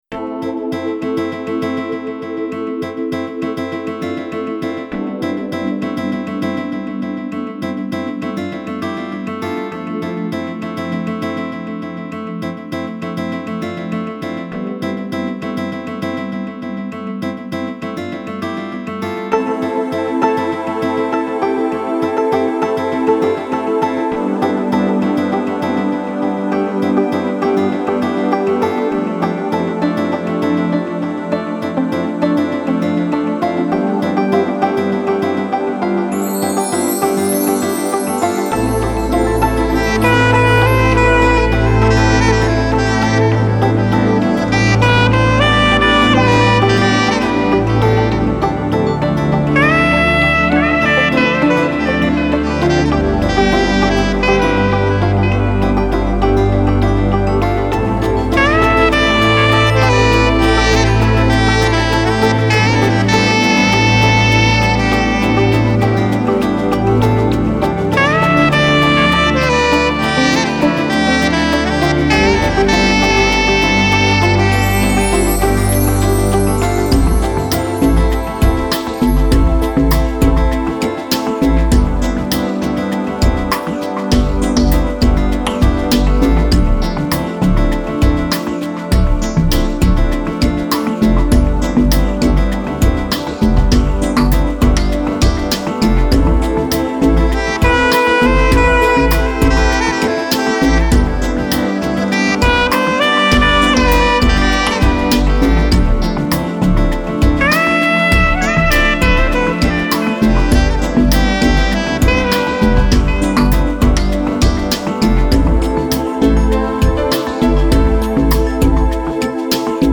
это атмосферная композиция в жанре chill-out